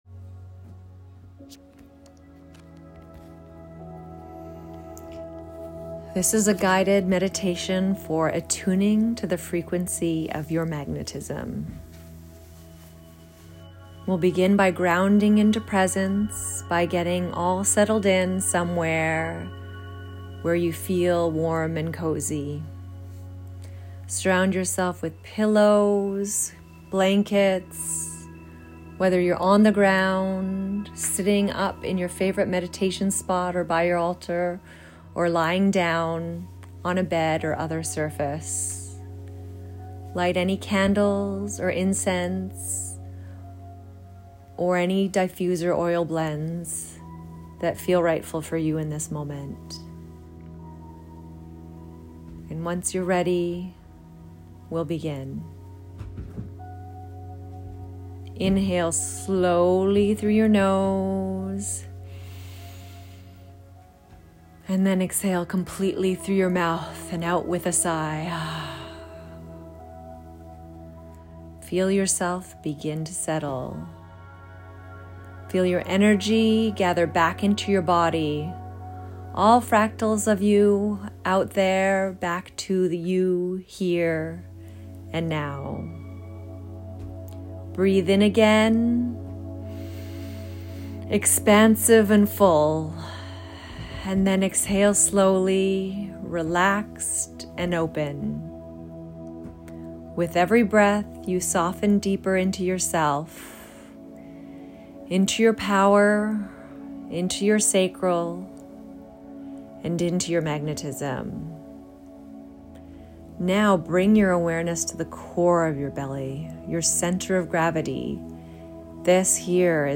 Guided Meditation: Attuning to Your Magnetism Frequency